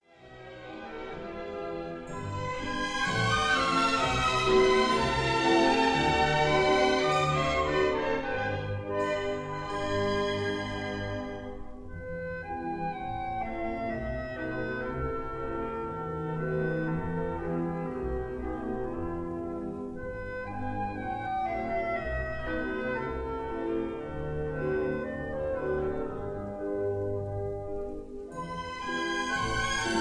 conductor
Recorded in the Kingsway Hall, London on 25 March 1953